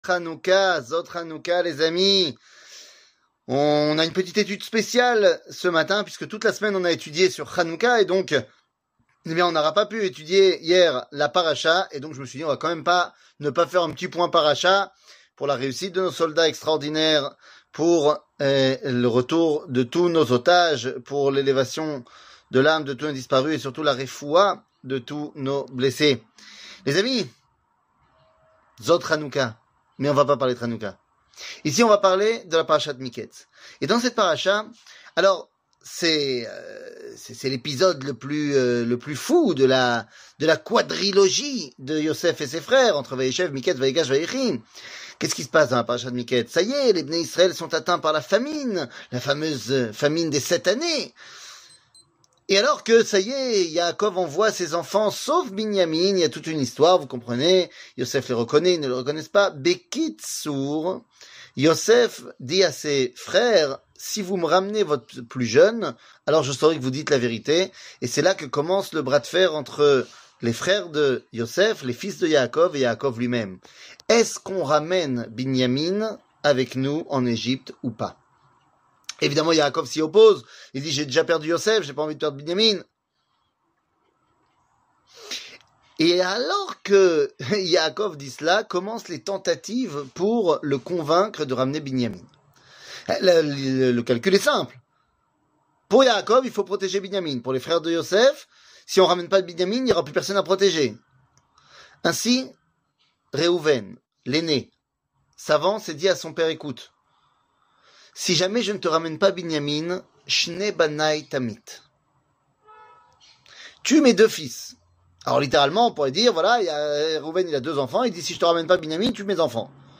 שיעור מ 15 דצמבר 2023